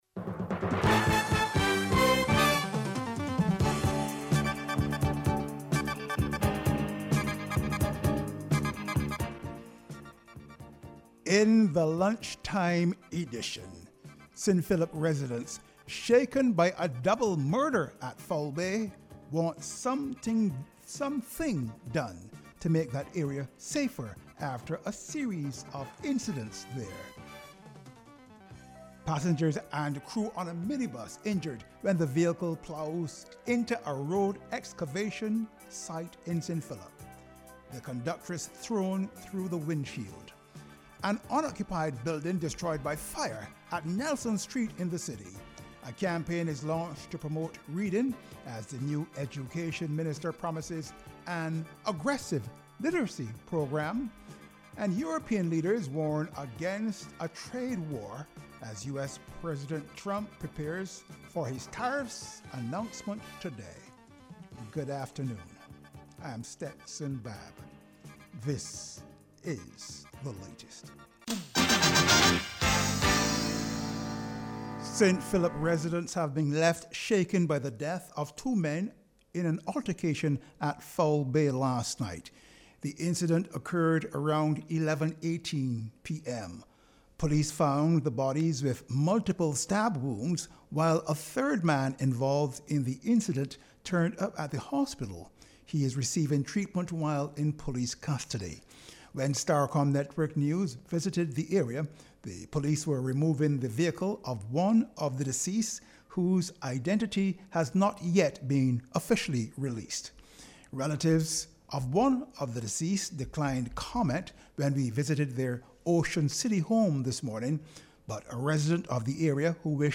The constitutional reform commission held it’s first ‘Have your say’ public consultation at the St. Michael School yesterday, which saw scores of Barbadians come out to share their recommendations on what a new constitution should look like.